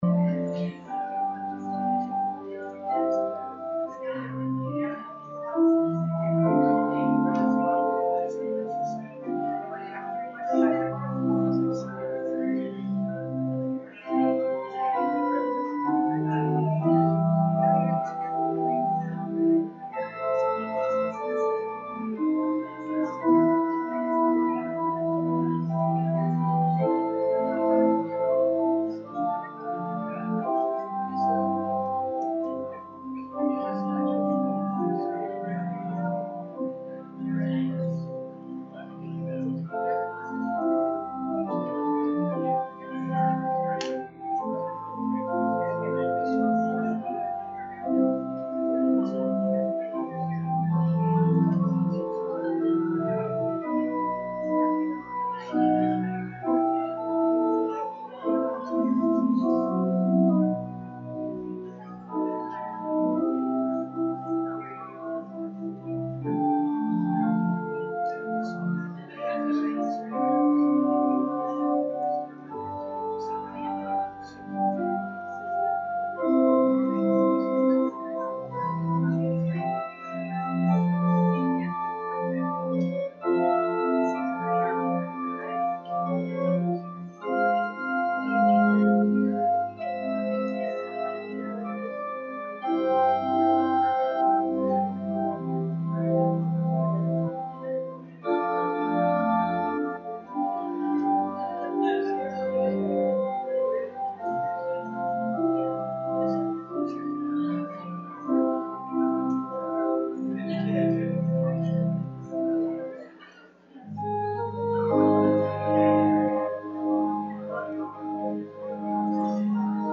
RUMC-service-Feb-5-2023.mp3